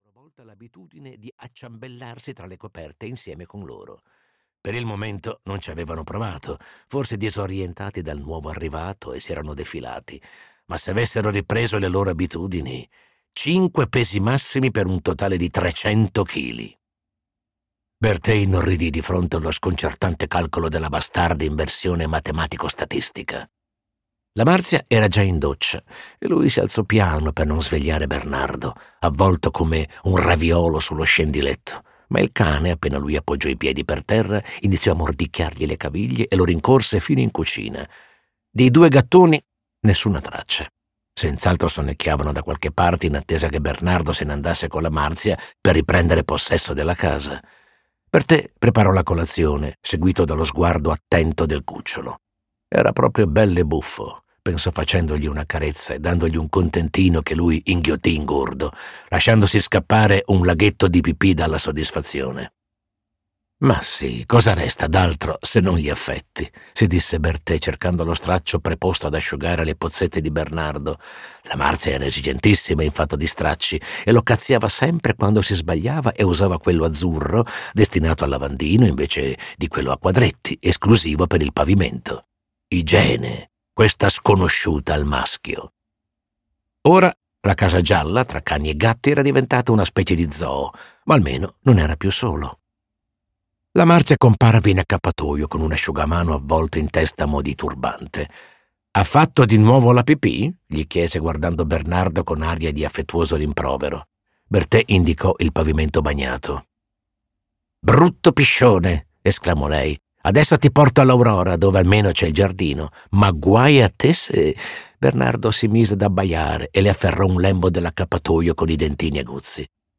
"Il caso Mariuz" di Emilio Martini - Audiolibro digitale - AUDIOLIBRI LIQUIDI - Il Libraio